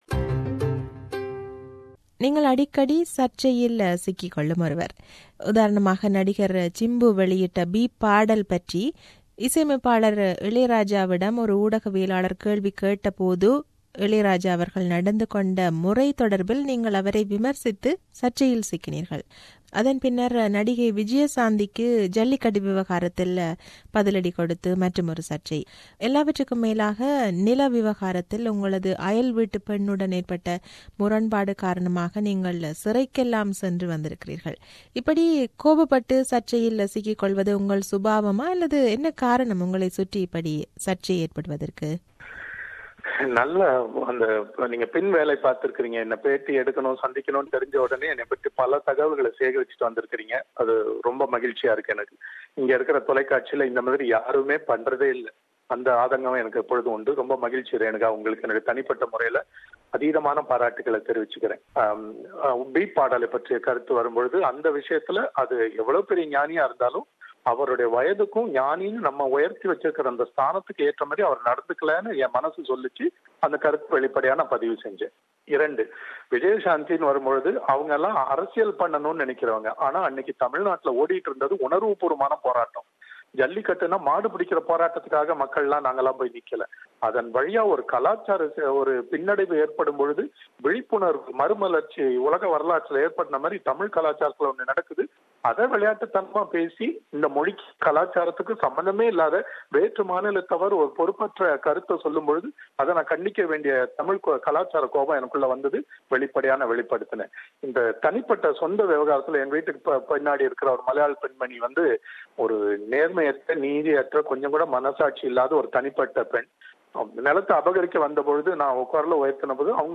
An Interview with James Vasanthan-P02
For more than a decade, he has worked as an anchor in popular Tamil satellite television channels like Sun TV, Vijay TV and Makkal TV before turning into a film music composer in the Tamil Film Industry. This is an interview with James Vasanthan.